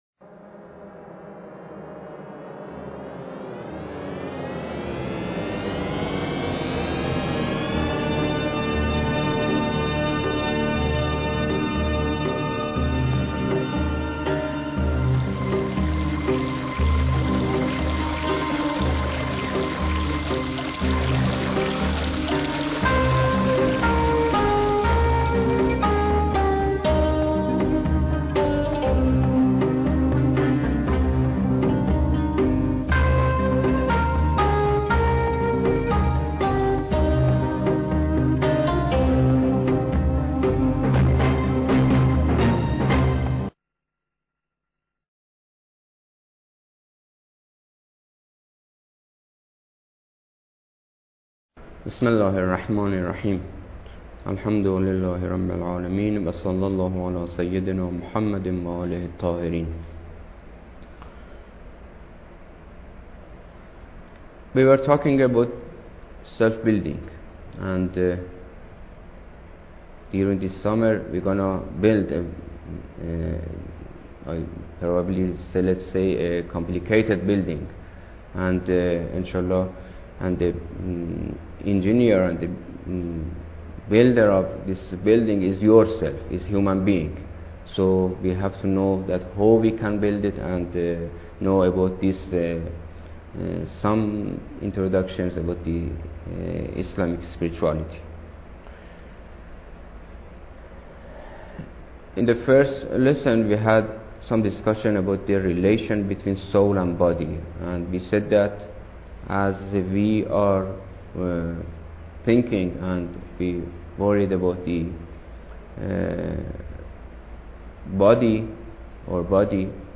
Lecture_2